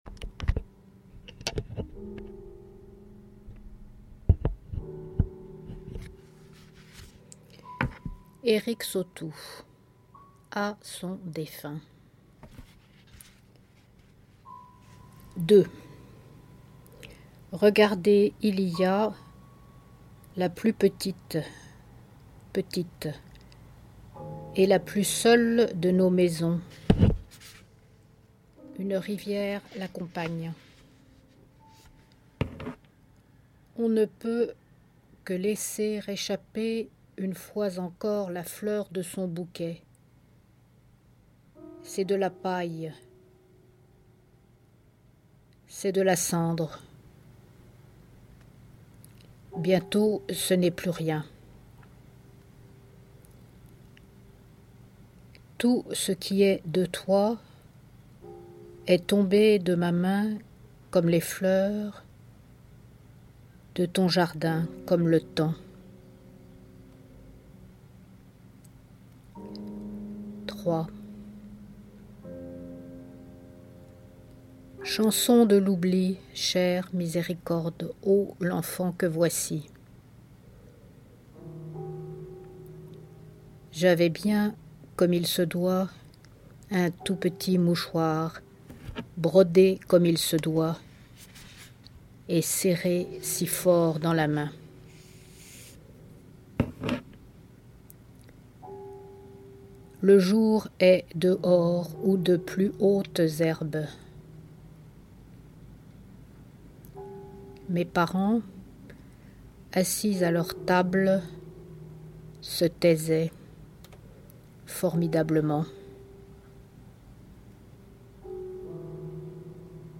MicroLectures d'extraits de quatre des livres reçus cette semaine par Poezibao.